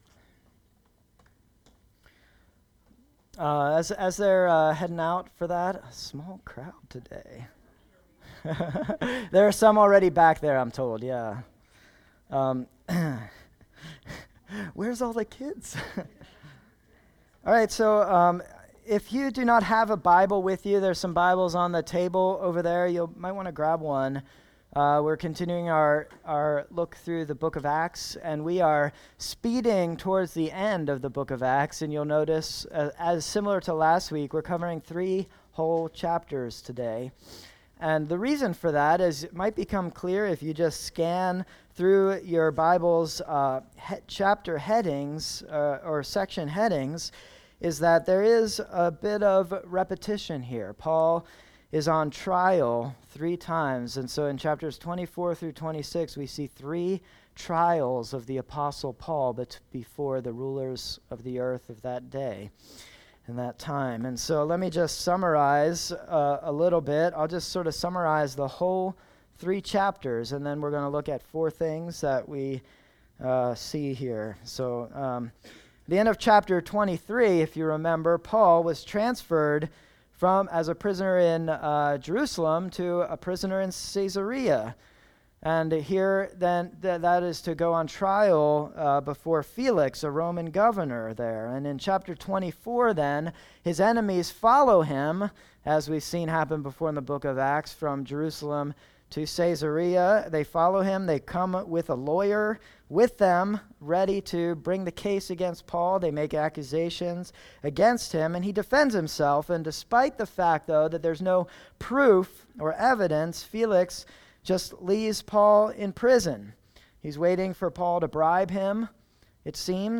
Sermon Text